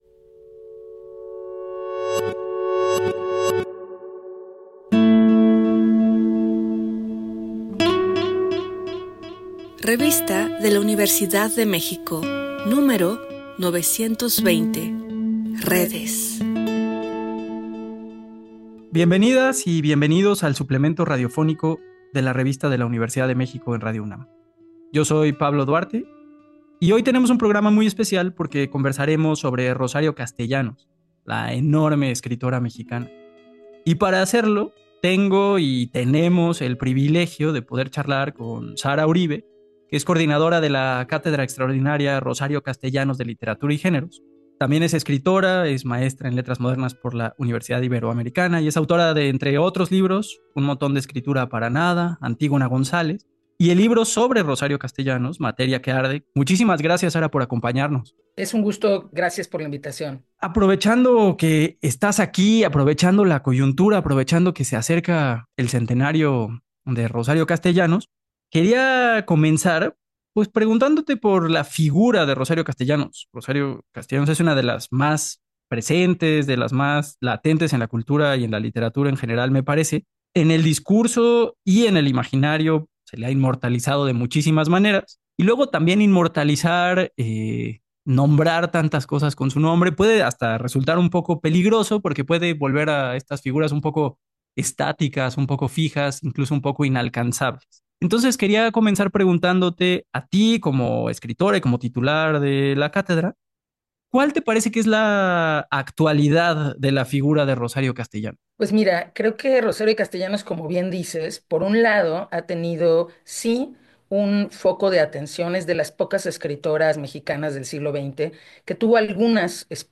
Cargar audio Este programa es una coproducción de la Revista de la Universidad de México y Radio UNAM. Fue transmitido el jueves 1° de mayo de 2025 por el 96.1 FM.